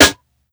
Banks Snare.wav